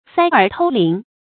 塞耳偷铃 sāi ěr tōu líng
塞耳偷铃发音
成语注音ㄙㄜˋ ㄦˇ ㄊㄡ ㄌㄧㄥˊ